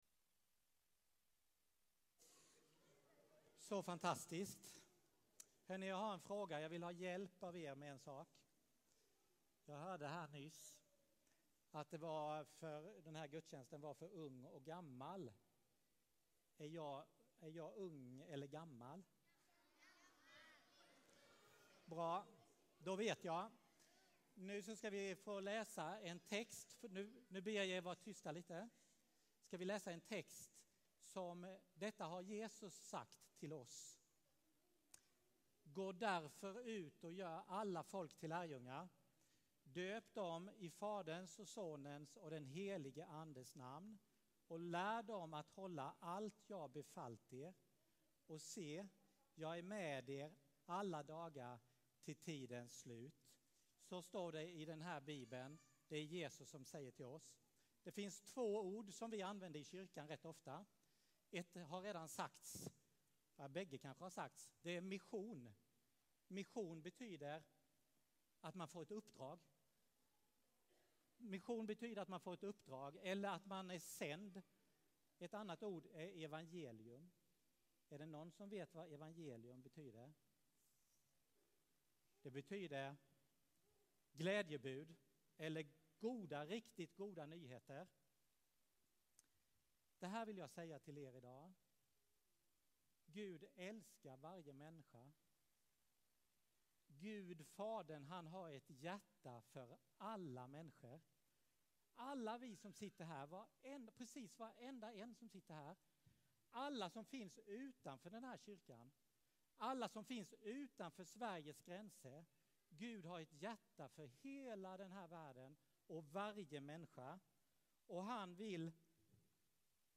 Gudstjänst för stora & små